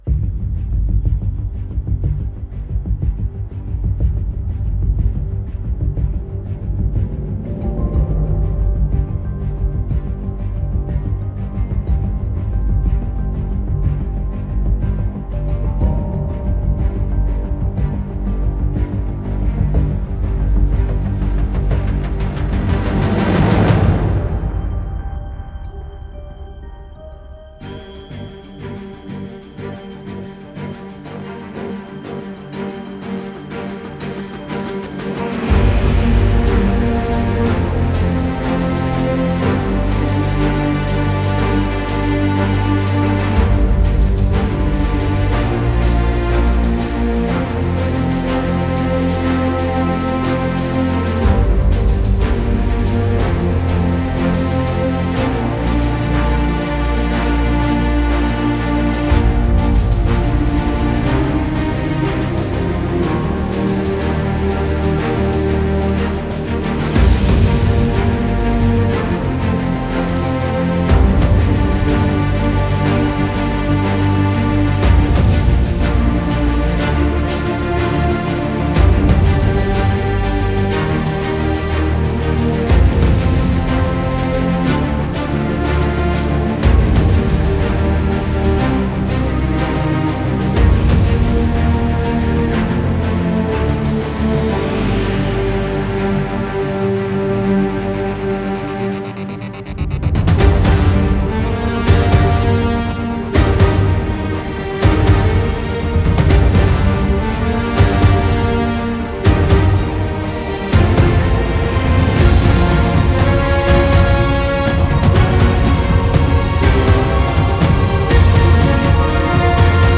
ambient.mp3